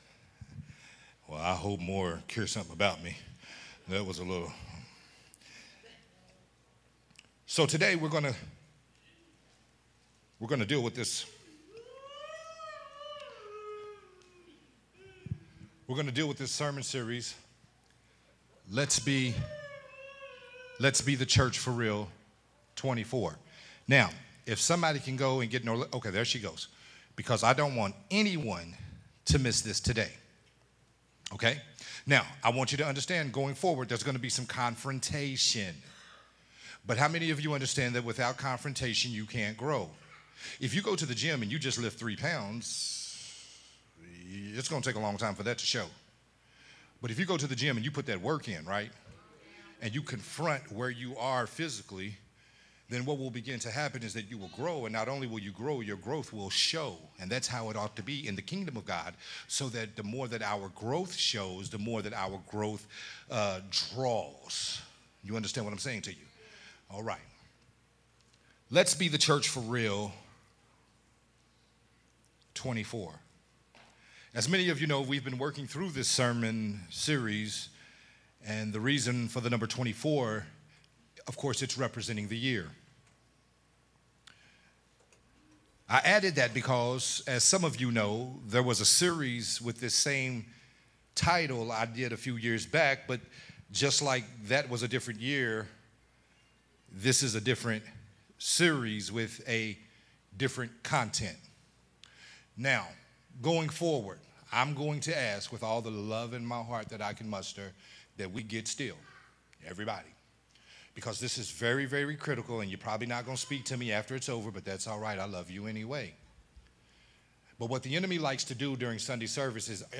Sunday Morning Worship Service sermon